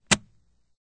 click_hard_snap.ogg